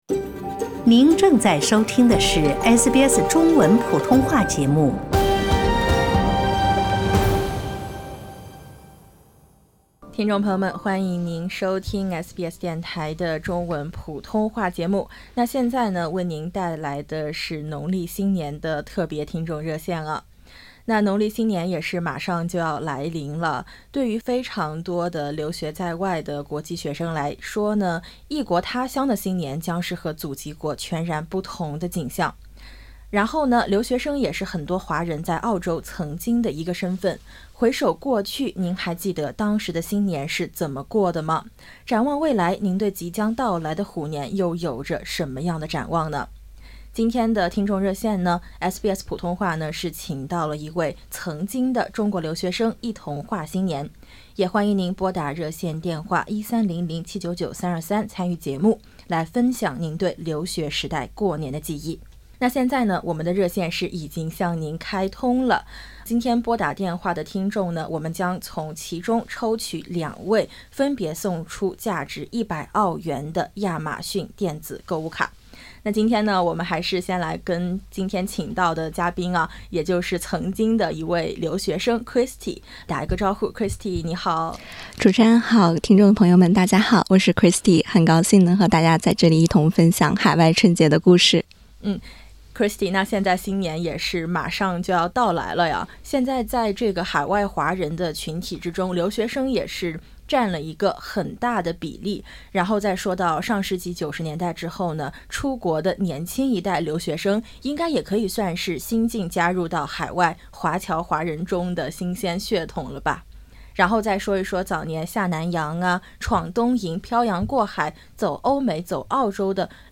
本期听众热线，SBS普通话节目请到一位90后中国留学生一同话新年。不少曾经的留学生也打进热线，分享不同年代华人对留学时代过年的记忆，欢迎点击封面音频，听新老留学生讲述在澳过年的故事。